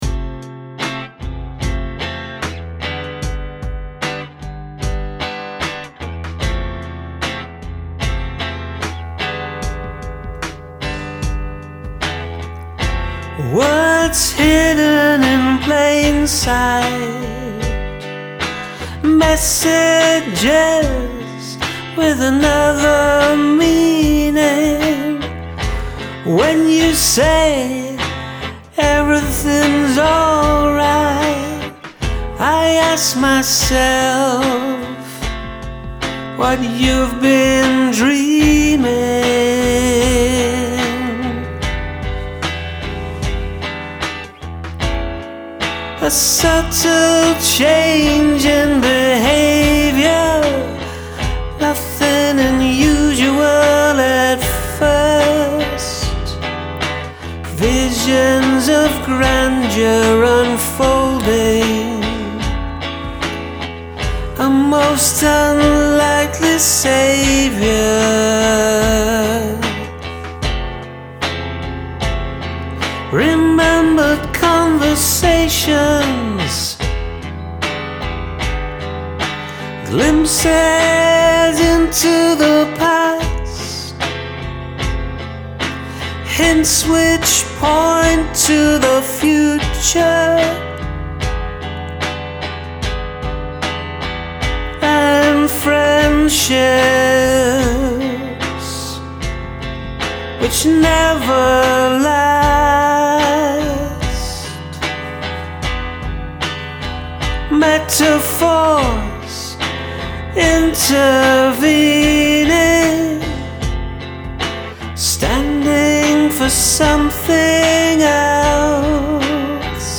love the funky dreamy chords here.